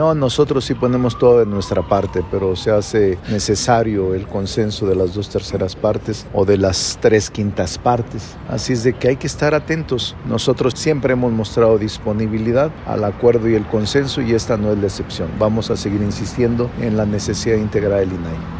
En una breve entrevista, el presidente de la Junta de Coordinación Política, declaró que se observará toda resolución de carácter jurisdiccional respecto a este tema.